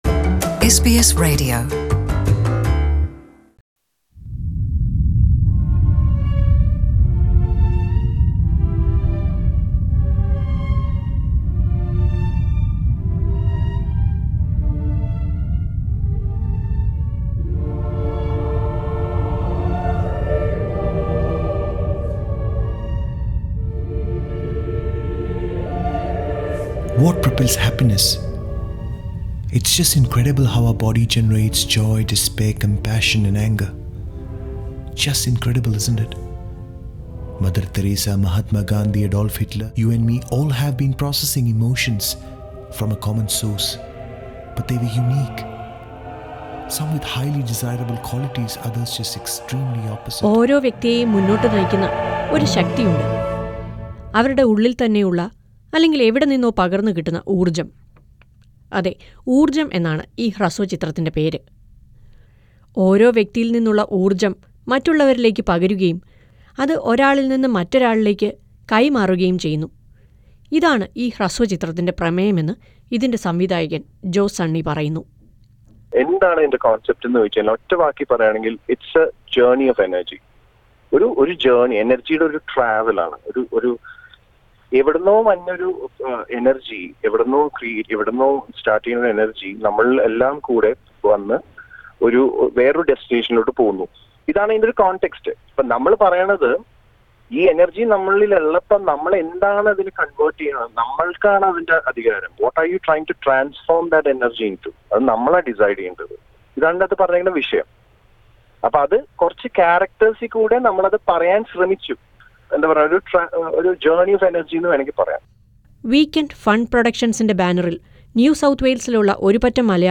Oorjjam is a short film created by a group of Malayalees in NSW. Listen to a report on Oorjjam.